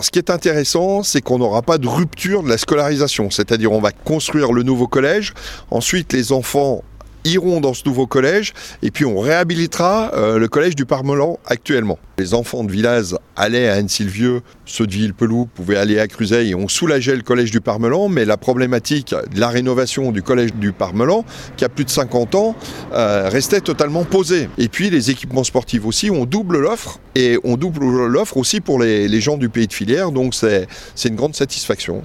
François Excoffier, conseiller départemental de la Haute-Savoie délégué aux grands projets :